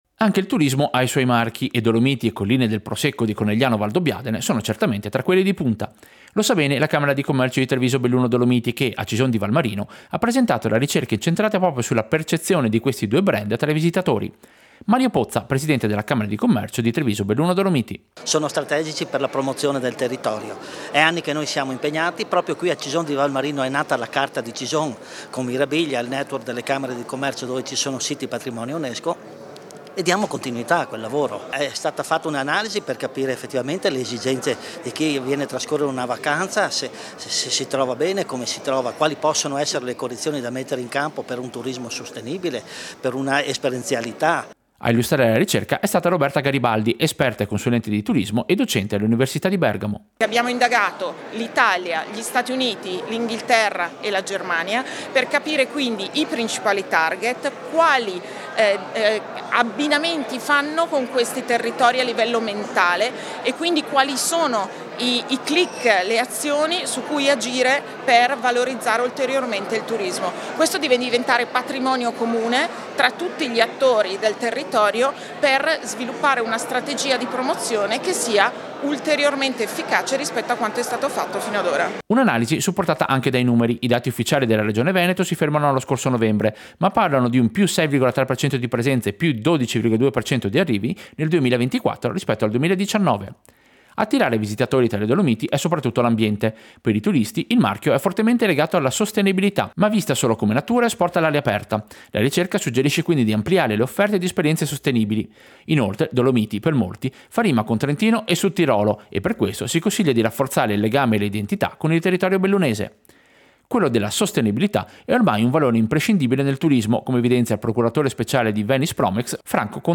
Servizio-Marchi-turistici-Cison-Camera-Commercio.mp3